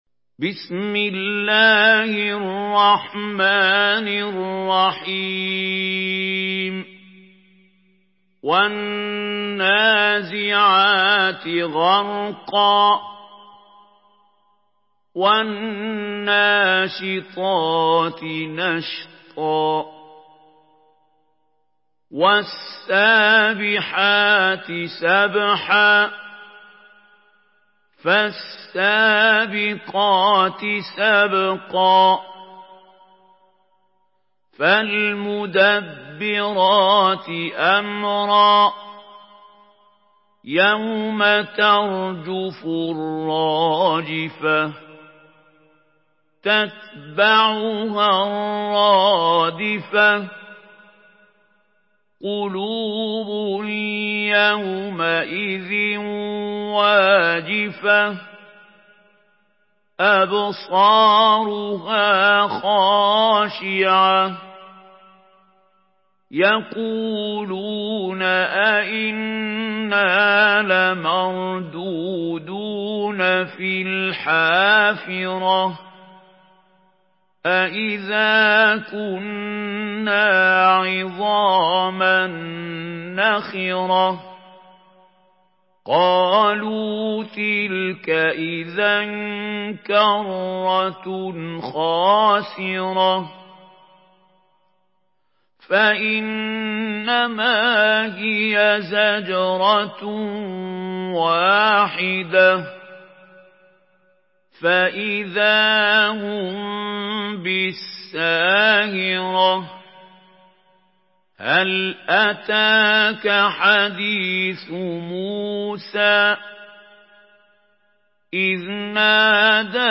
Surah আন-নাযি‘আত MP3 by Mahmoud Khalil Al-Hussary in Hafs An Asim narration.
Murattal Hafs An Asim